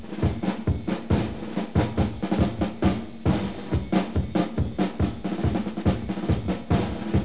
drum1.wav